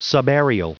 Prononciation du mot : subaerial
subaerial.wav